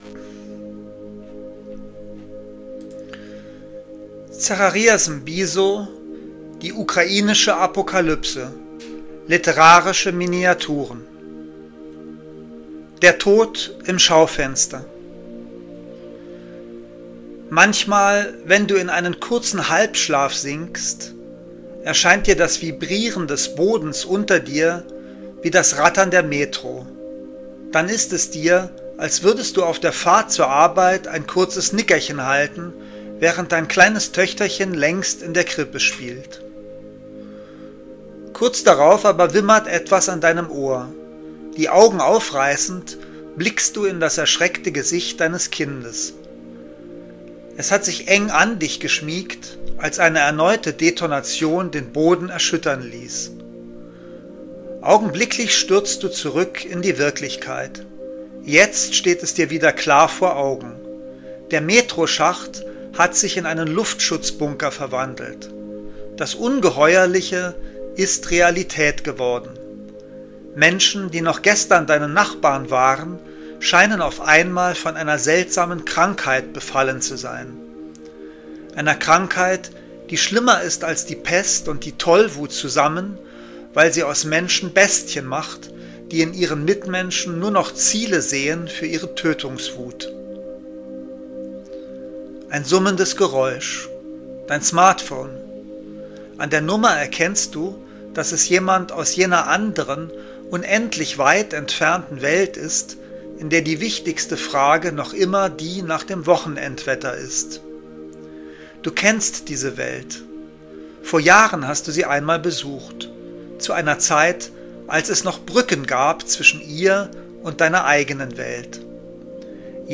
Lesung aus: Die ukrainische Apokalypse